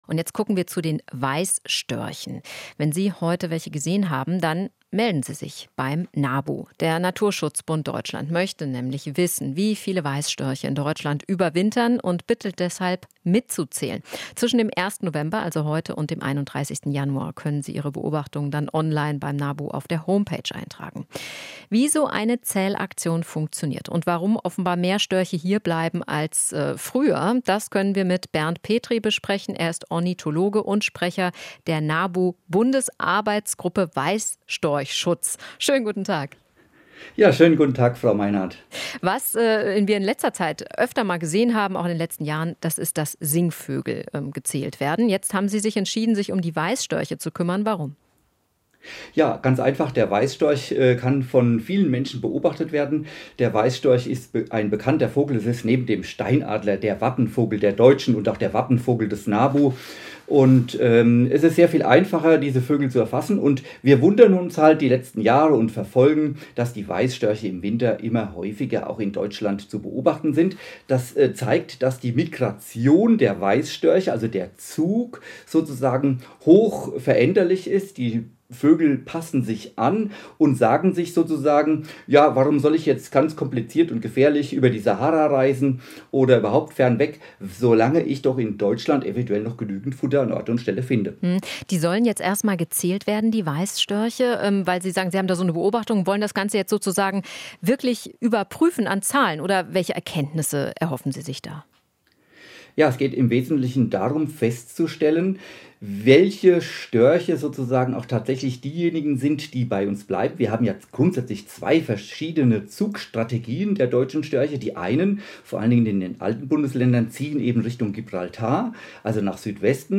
Interview - Zählaktion: Nabu will Zugstrategie der Störche besser verstehen